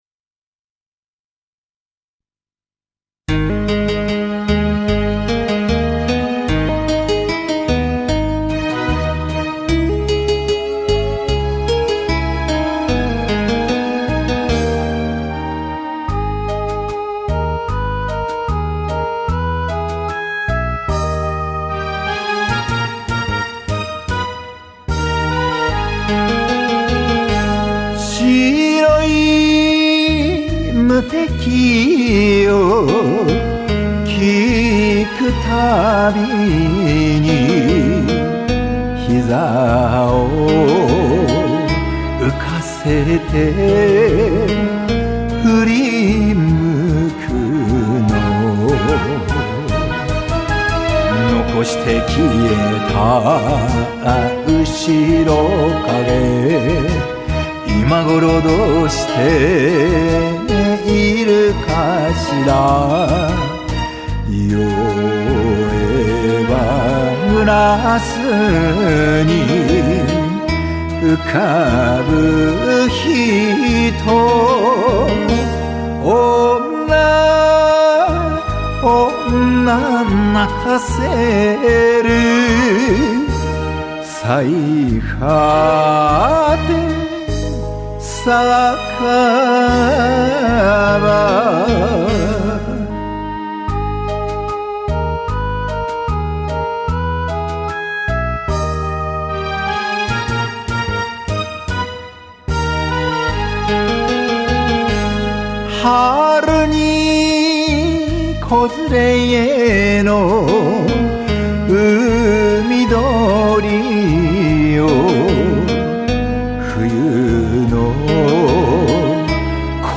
さいはて酒場 77コンサート 2013/7/7 フレサよしみ